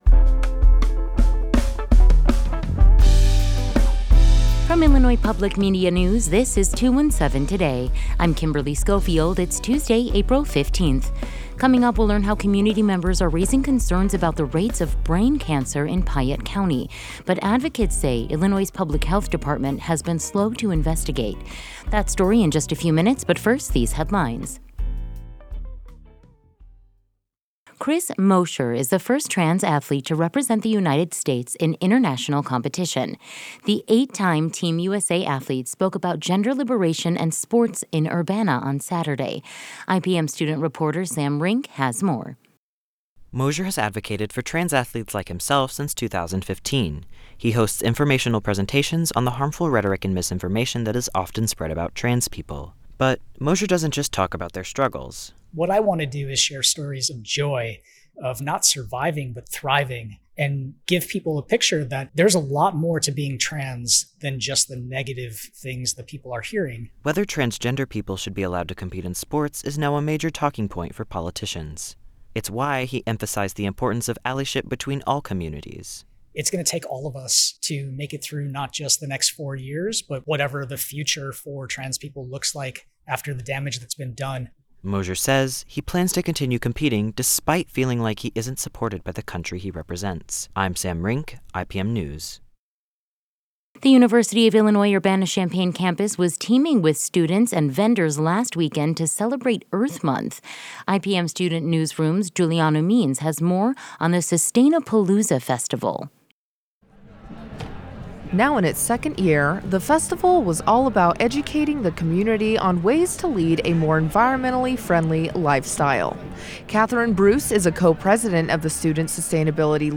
217 Today: Investigative reporters discuss possible cancer cluster in Piatt County